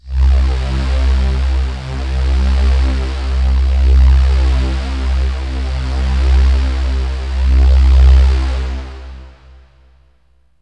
AMBIENT ATMOSPHERES-3 0002.wav